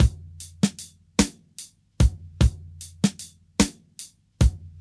• 75 Bpm Drum Loop Sample C Key.wav
Free drum loop - kick tuned to the C note. Loudest frequency: 1077Hz
75-bpm-drum-loop-sample-c-key-oYx.wav